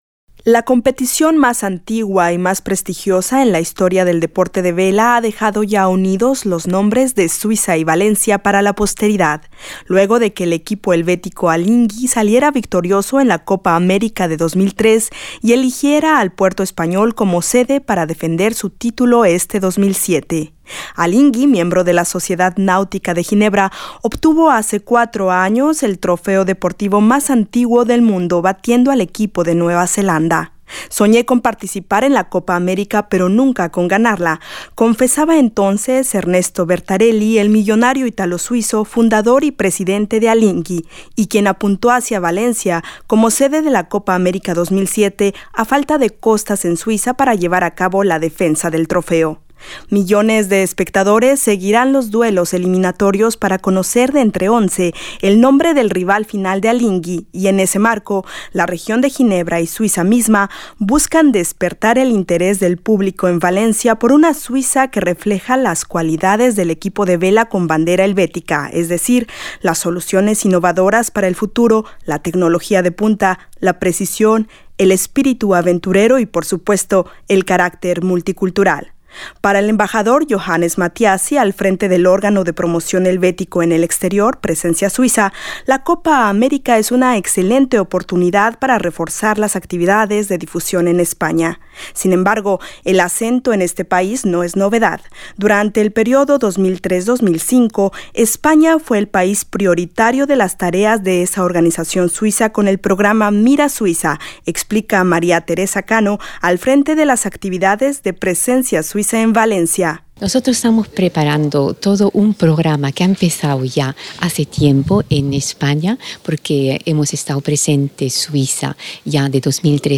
Valencia, sede en la costa mediterránea del equipo de vela suizo, Alinghi, defensor de la Copa América, es centro de atención de las tareas de promoción de Presencia Suiza (PRS) en España. Arquitectura, economía, ciencia, educación y juventud han formado parte del programa en el puerto mediterráneo. Un reportaje